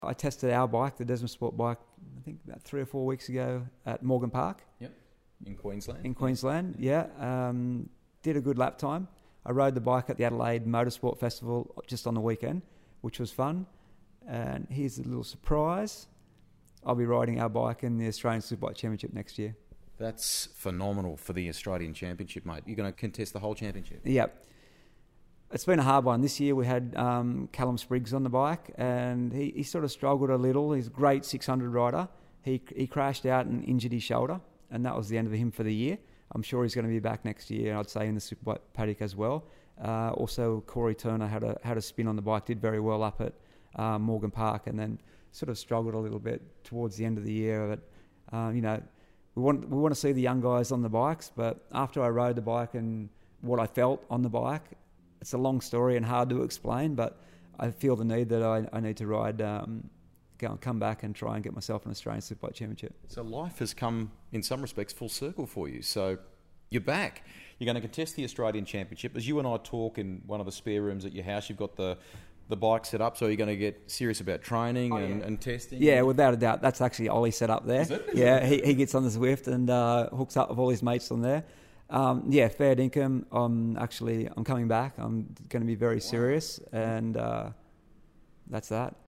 Beluister het hele verhaal en de beweegreden uit de mond van Bayliss zelf via onderstaande audiolink: